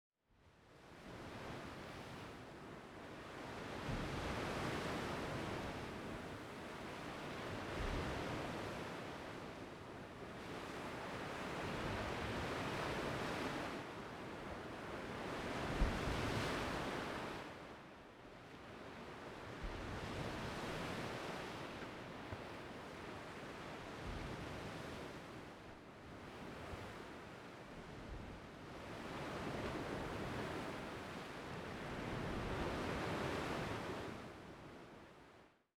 Ocean Waves Crashing.wav